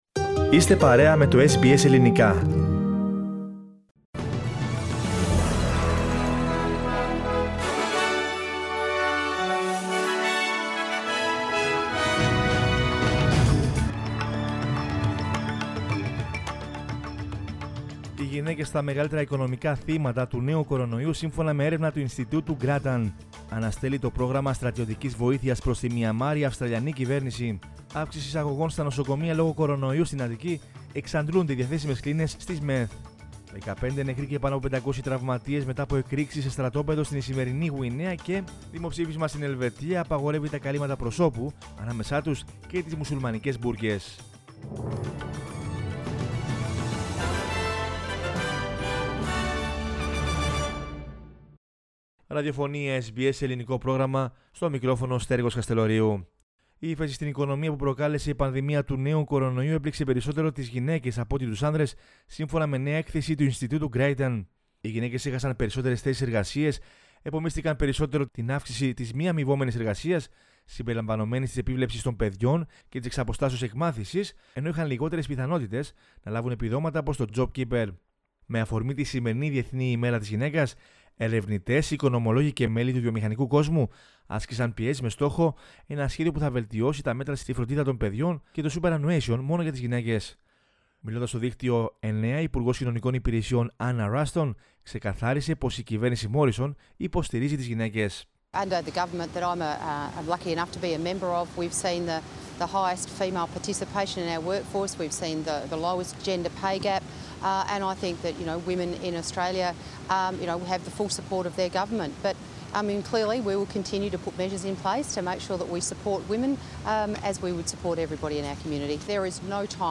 News in Greek from Australia, Greece, Cyprus and the world is the news bulletin of Monday 8 March 2021.